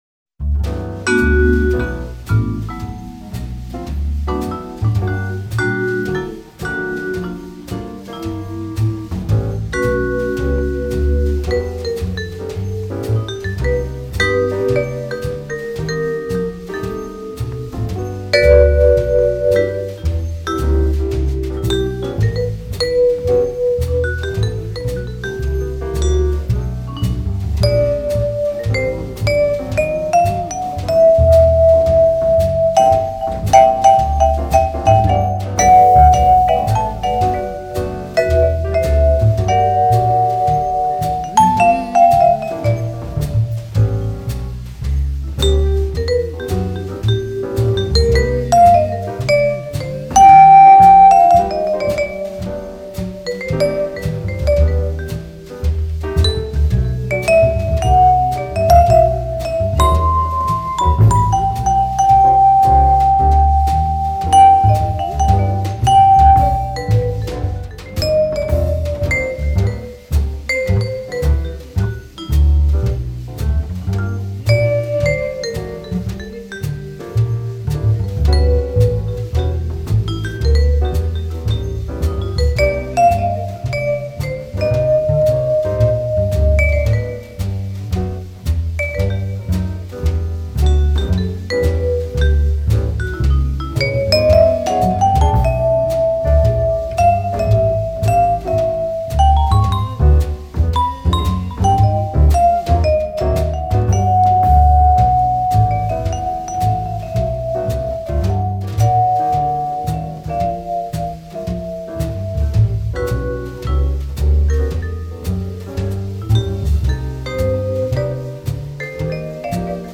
West Coast Cool School
vibraphonist
bass
drums
piano
Cool and Mellow